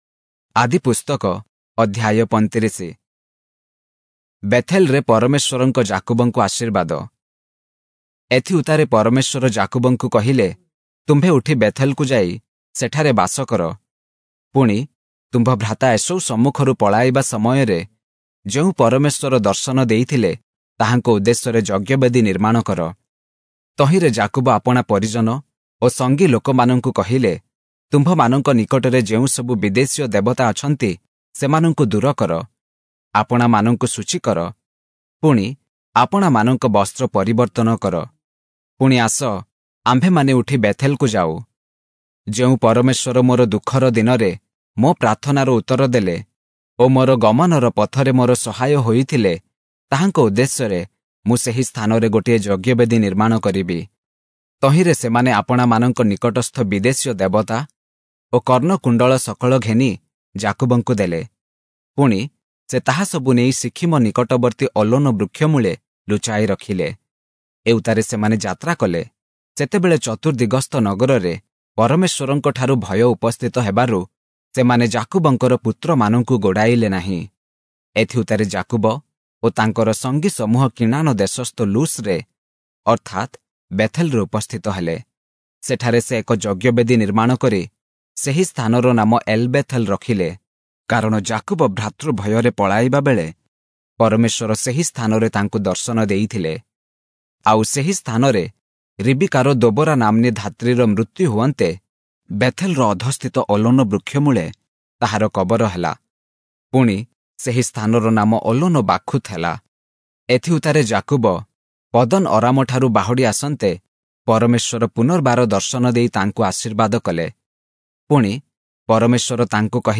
Oriya Audio Bible - Genesis 20 in Irvor bible version